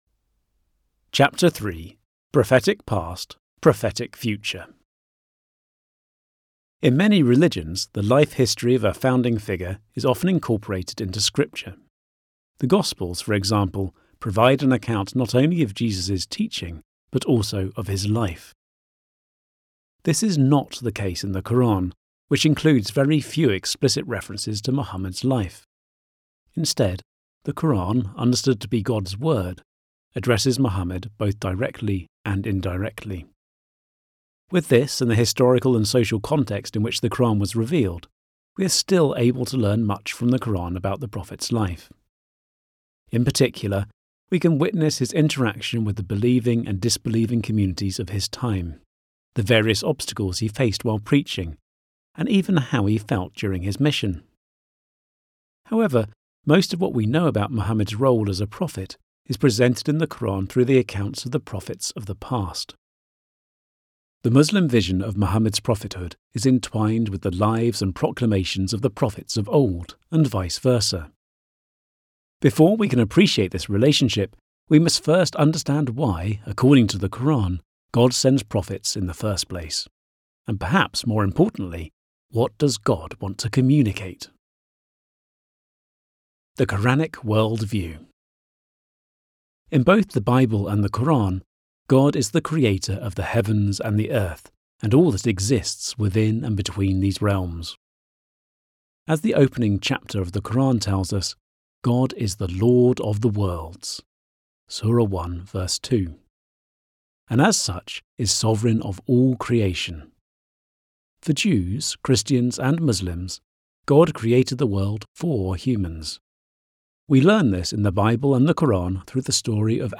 Audiobook (MP3) Ebook (ePUB) Ebook (PDF) English General interest World of Islam